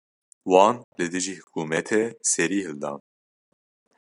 /hɪlˈdɑːn/